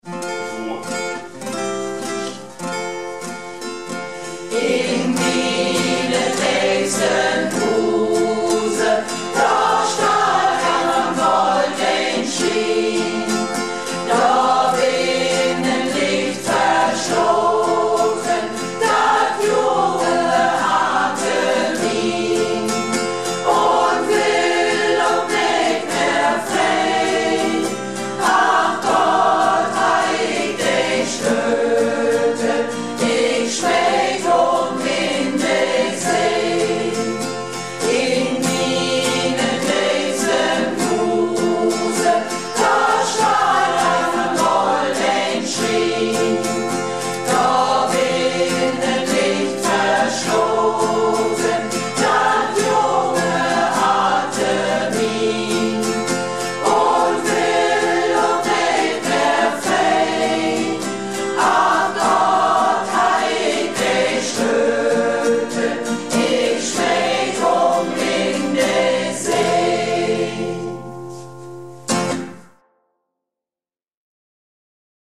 Malle Diven - Probe am 12.09.17